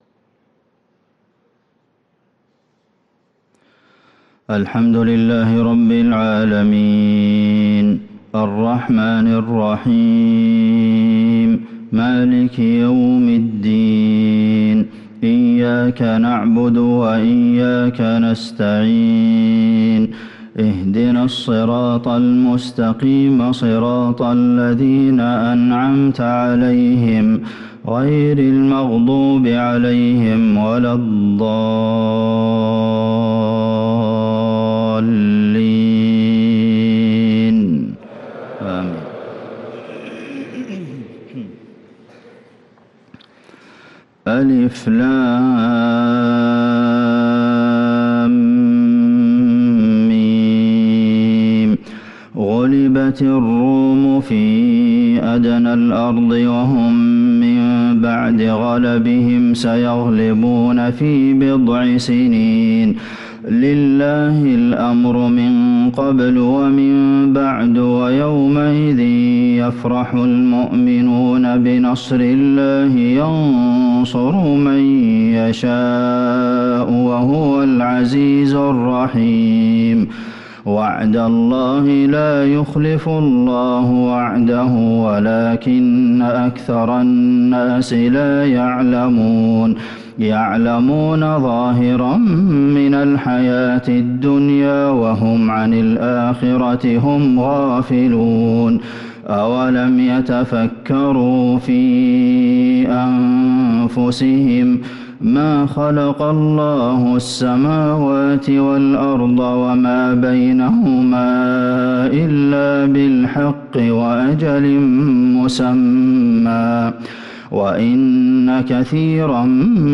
صلاة الفجر للقارئ عبدالمحسن القاسم 3 ربيع الأول 1445 هـ
تِلَاوَات الْحَرَمَيْن .